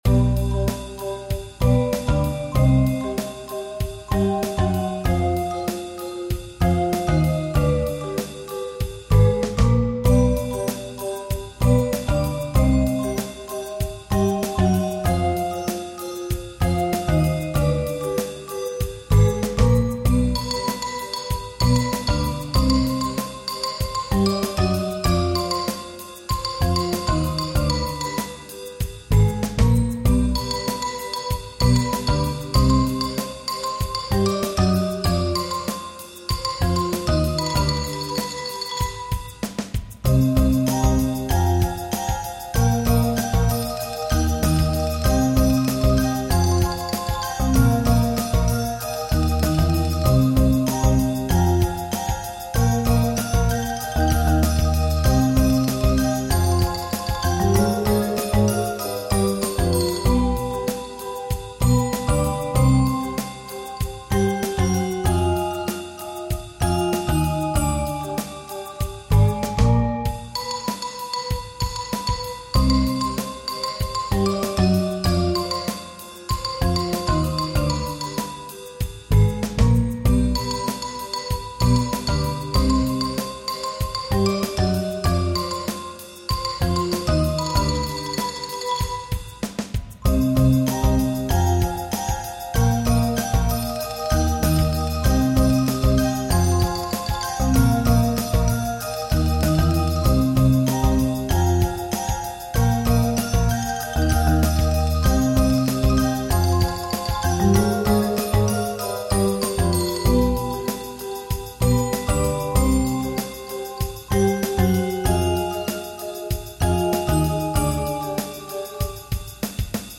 Mallet-Steelband Muziek